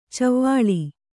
♪ cavvāḷi